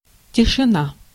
Ääntäminen
IPA: /kalm/